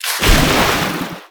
Sfx_creature_penguin_dive_deep_02.ogg